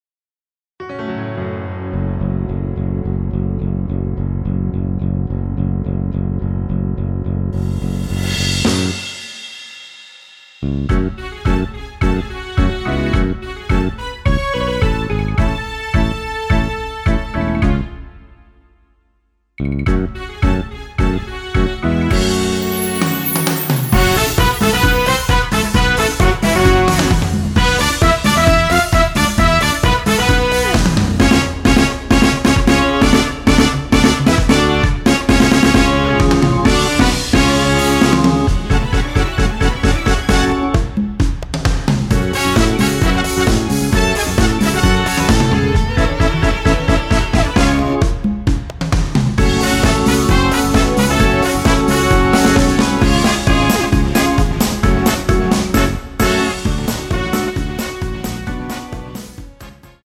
F#m
앞부분30초, 뒷부분30초씩 편집해서 올려 드리고 있습니다.
중간에 음이 끈어지고 다시 나오는 이유는